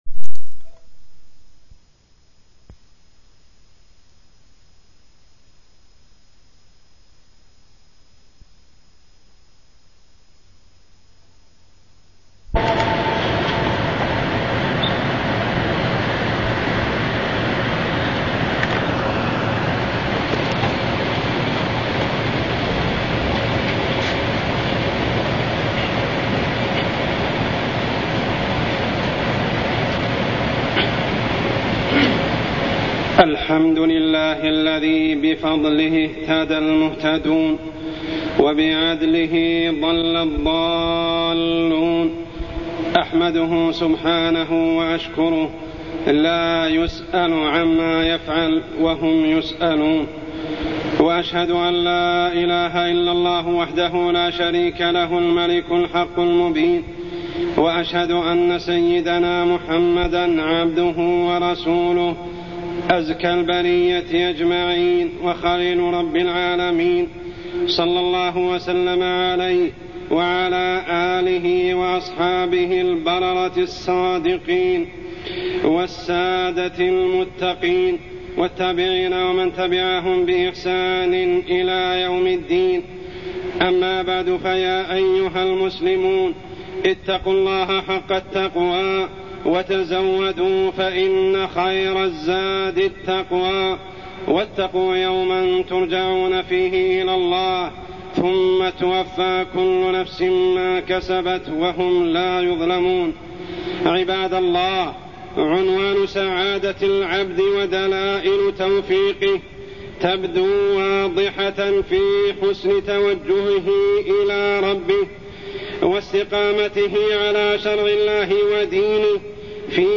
تاريخ النشر ١ جمادى الأولى ١٤٢١ هـ المكان: المسجد الحرام الشيخ: عمر السبيل عمر السبيل حقيقة الدنيا